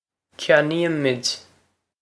Pronunciation for how to say
kyan-ee-ih-mwid
This is an approximate phonetic pronunciation of the phrase.